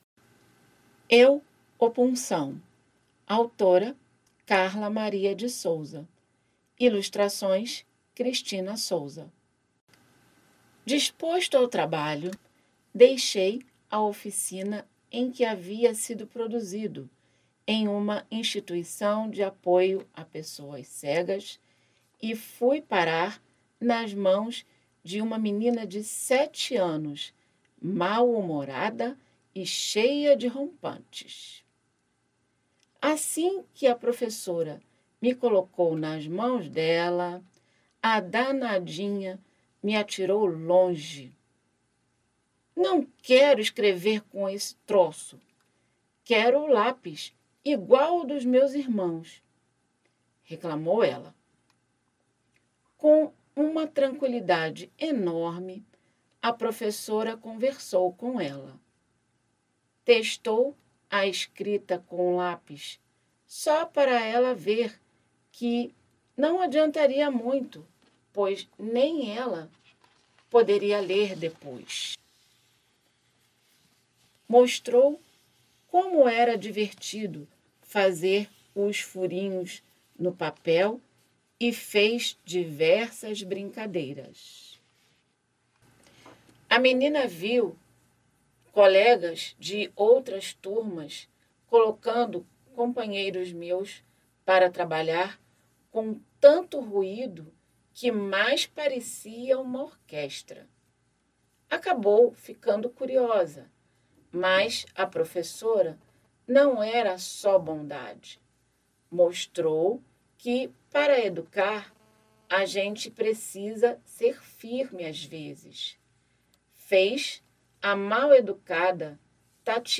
audiolivro-publicacao-infantojuvenil_eu-o-puncao_2023.mp3